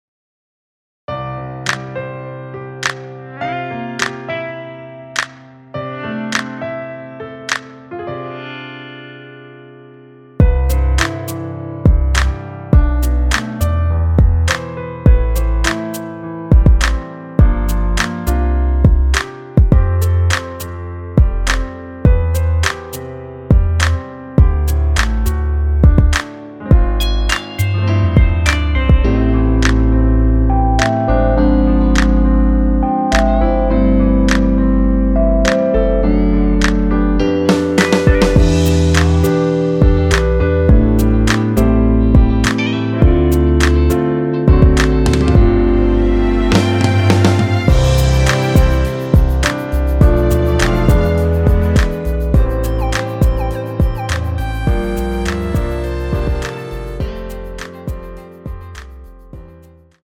랩없이 혼자 부를수 있는 버전의 MR입니다.
앞부분30초, 뒷부분30초씩 편집해서 올려 드리고 있습니다.
위처럼 미리듣기를 만들어서 그렇습니다.